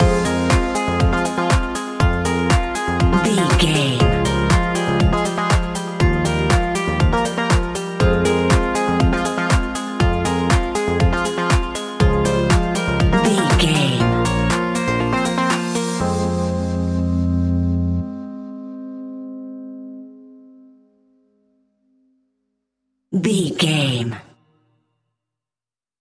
Fast paced
Aeolian/Minor
groovy
uplifting
driving
energetic
drum machine
synthesiser
funky house
deep house
nu disco
upbeat
synth bass
synth leads